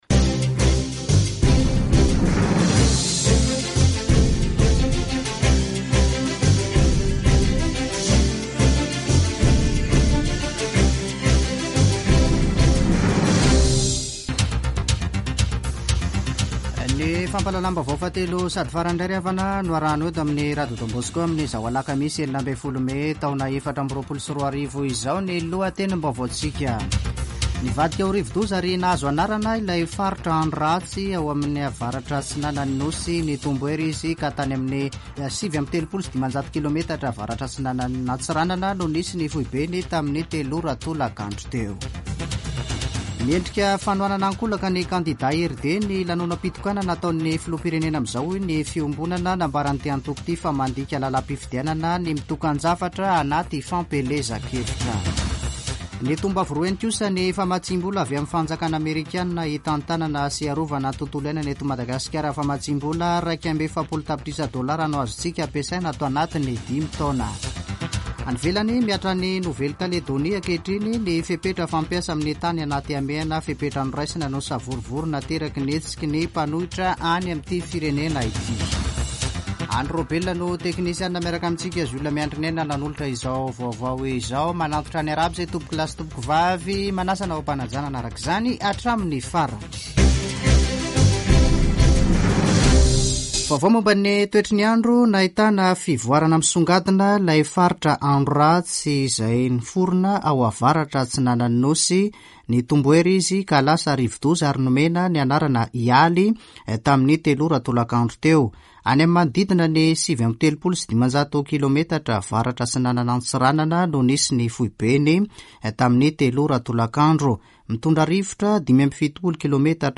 [Vaovao hariva] Alakamisy 16 mey 2024